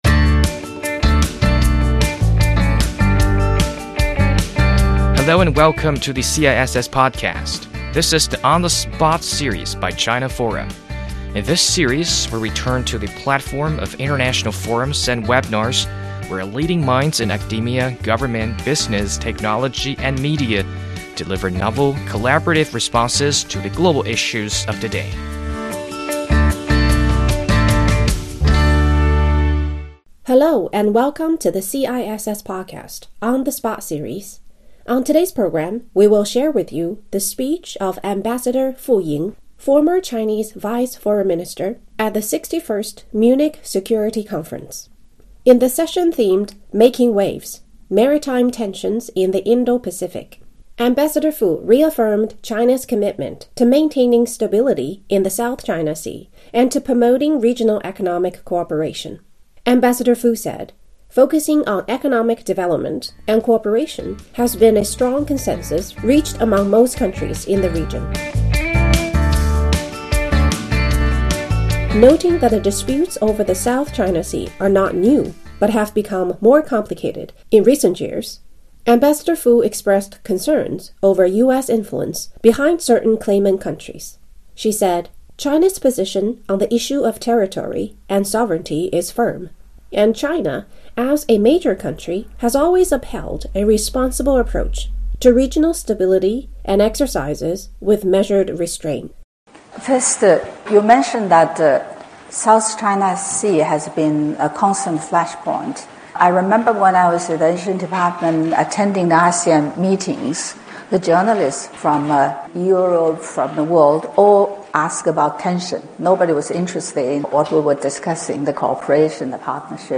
中国外交部前副部长傅莹应邀出席会议，并在“造浪：印太的海上紧张局势”（Making Waves: Maritime Tensions in the Indo-Pacific）分论坛上参加讨论。
本期播客精选了傅莹大使的发言，以飨听众。